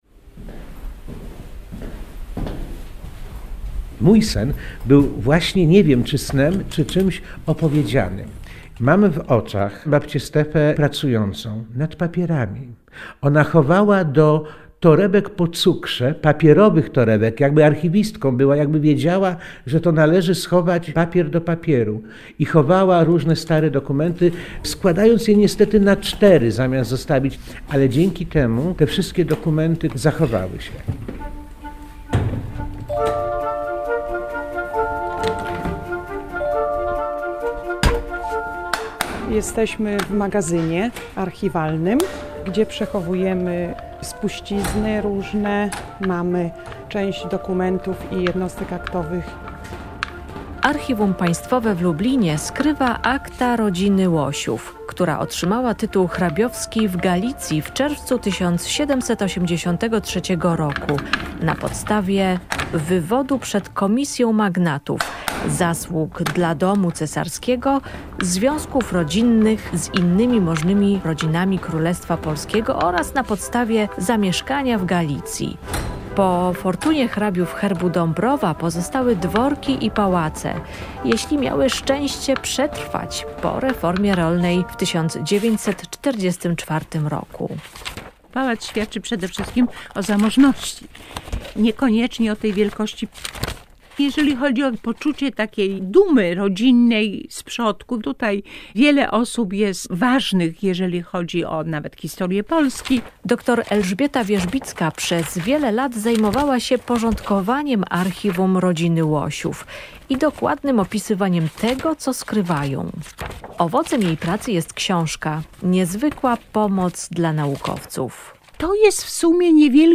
A po reportażu relacja z Dnia Darczyńcy, który organizowany jest przez lubelskie Archiwum Państwowe 6 grudnia.